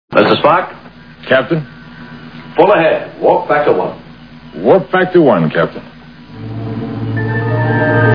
Sfx: Warp drive.